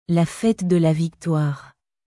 La fête de la victoireラ フェトゥ ドゥ ラ ヴィクトワール